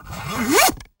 inventory_open.wav